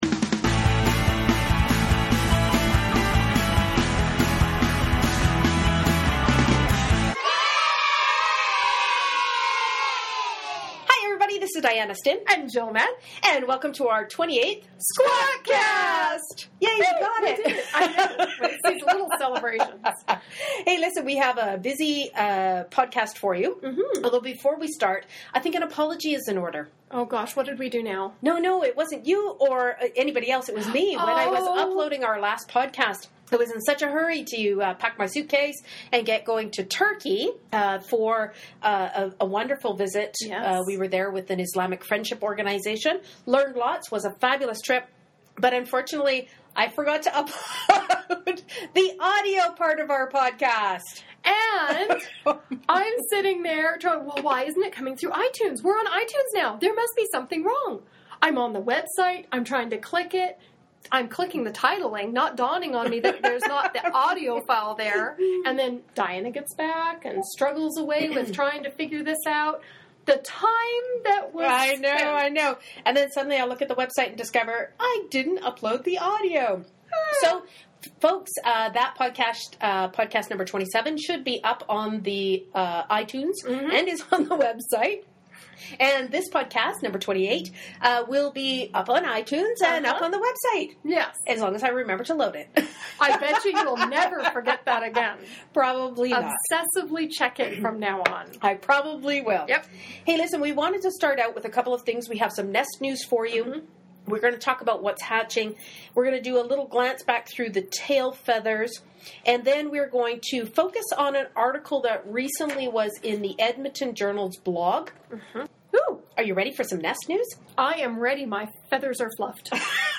Warning: Spontaneous burst of laughter may occur so watch your coffee cup!